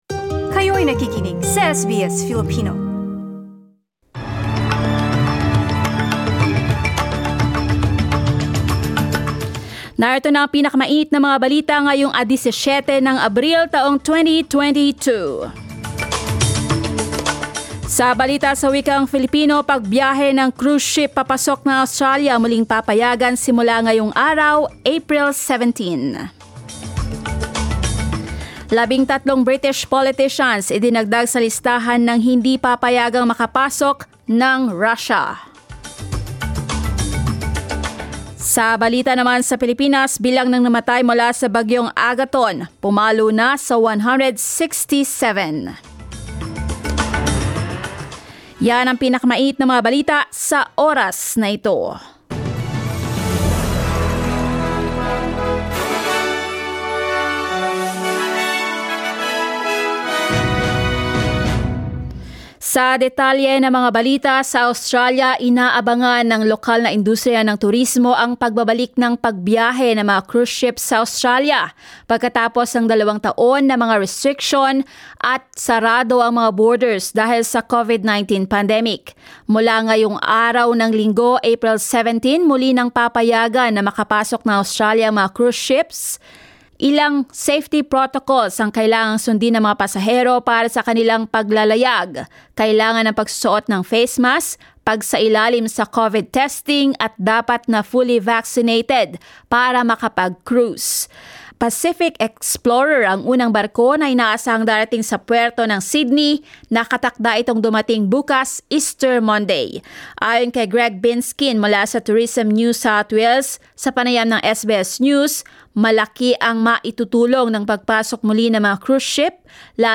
SBS News in Filipino, Sunday 17 April